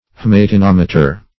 Haematinometer \H[ae]m`a*ti*nom"e*ter\, n.